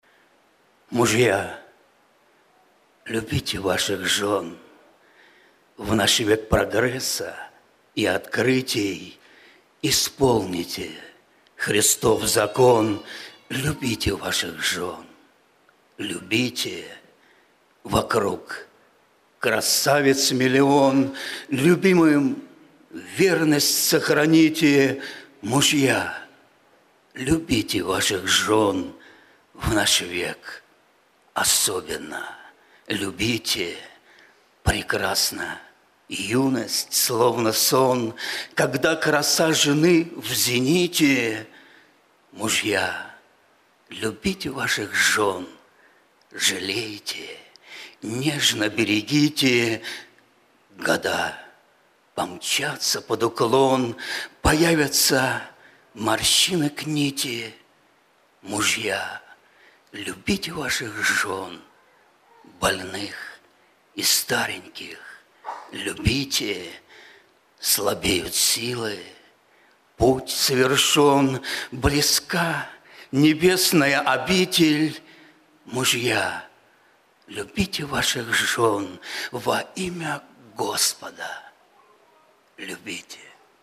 Богослужение 15.09.2024
Стихотворение